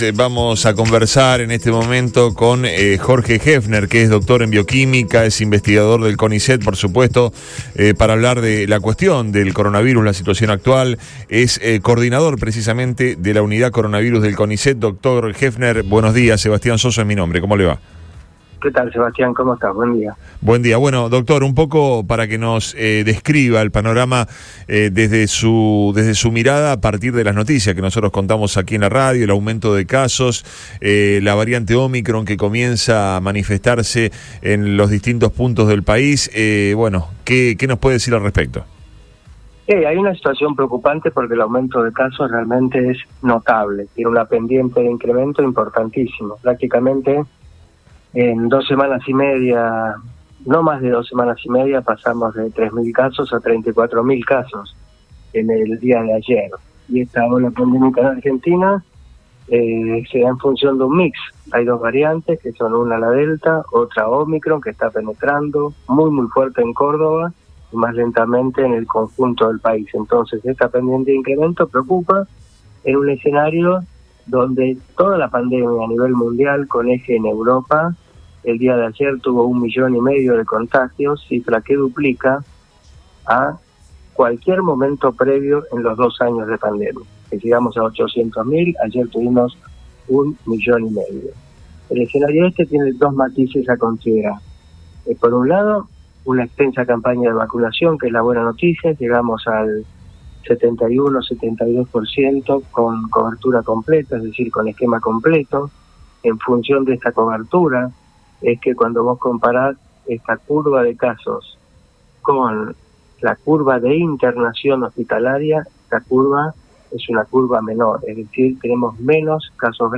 En diálogo con Antes de Todo por Radio Boing, el especialista aseguró que el escenario “es preocupante” por el incremento “notable” de contagios.